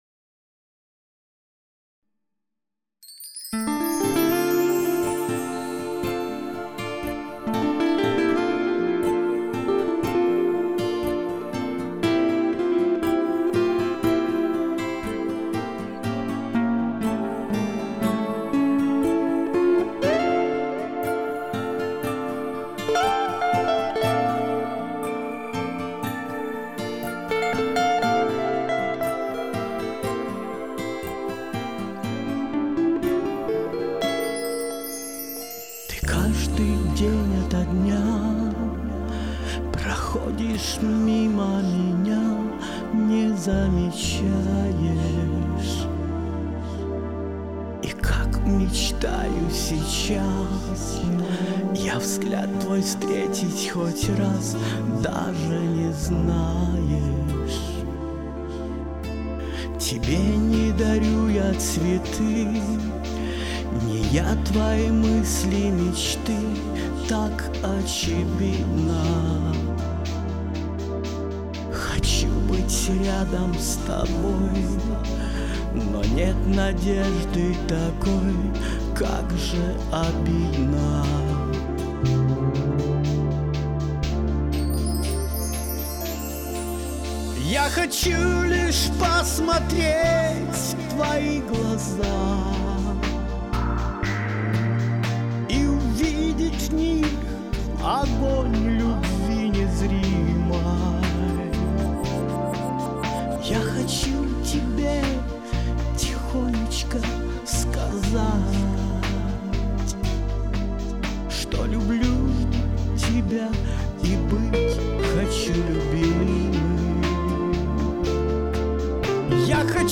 Второй вариант более мелодичен!поэтому 2:3